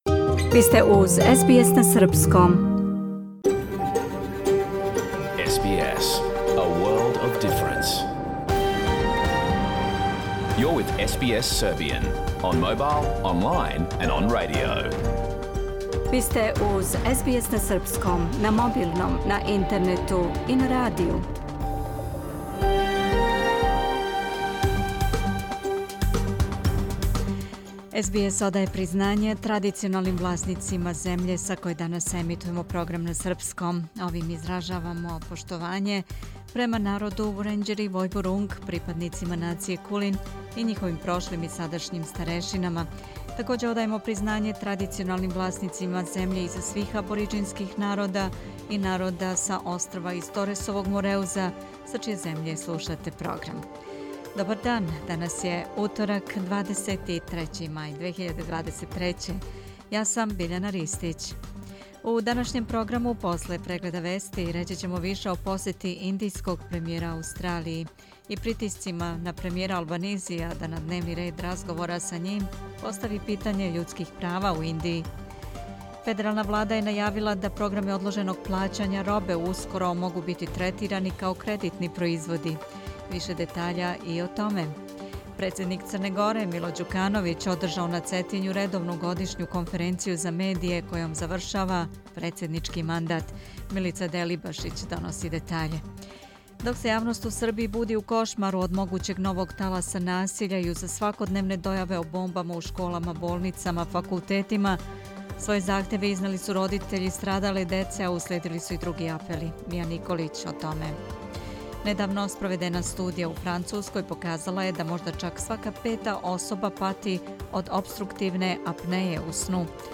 Програм емитован уживо 23. маја 2023. године
Ако сте пропустили данашњу емисију, можете да је слушате у целини као подкаст, без реклама.